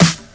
• Old School Clean Lo-Fi Steel Snare Drum E Key 92.wav
Royality free snare drum tuned to the E note. Loudest frequency: 2337Hz
old-school-clean-lo-fi-steel-snare-drum-e-key-92-vgS.wav